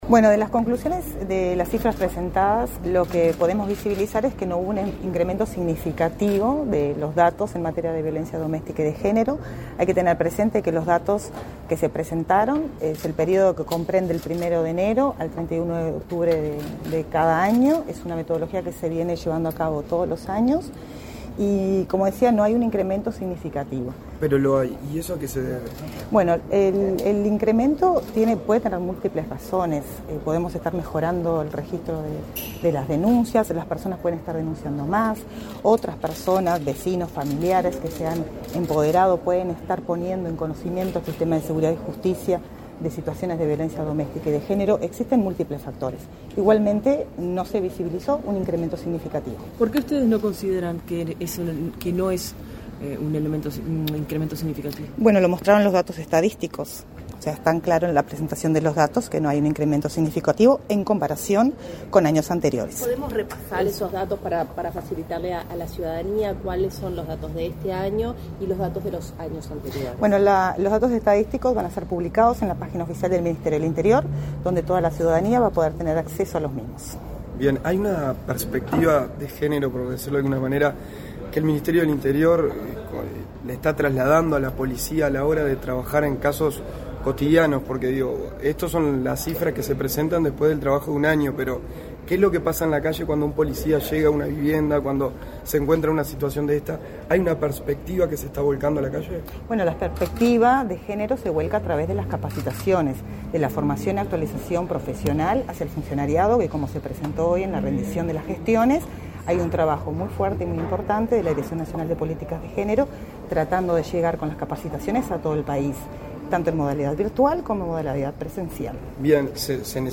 Declaraciones a la prensa de la directora nacional de Políticas de Género del Ministerio del Interior, Angelina Ferreira
En el marco del Día Internacional de Eliminación de la Violencia contra la Mujer, el ministro del Interior, Luis Alberto Heber, participó, este 29 de noviembre, de la rendición de cuentas en materia de violencia doméstica y de género. Tras la ceremonia, la responsable de la Dirección de Políticas de Género del ministerio, Angelina Ferreira, realizó declaraciones a la prensa.